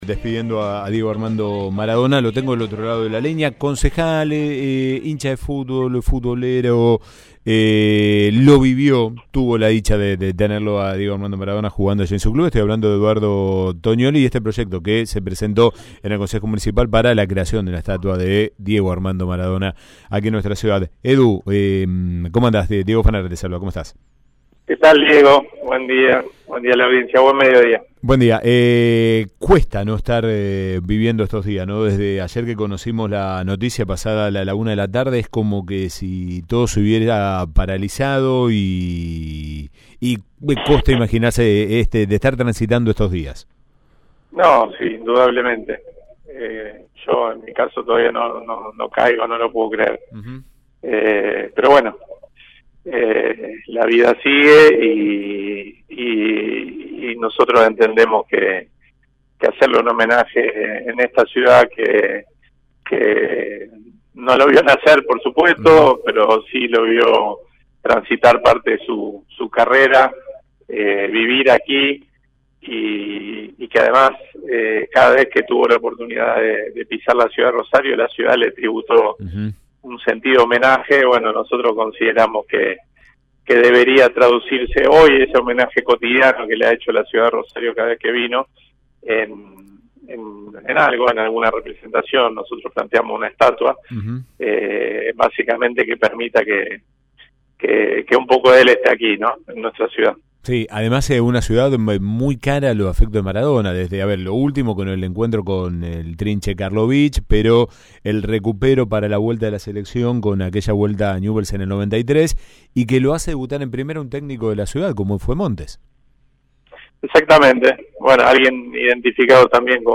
El Concejo aprobó sobre tablas un proyecto impulsado por Eduardo Toniolli y firmado por todos los bloques políticos. El concejal del Frente de Todos habló al respecto con AM 1330.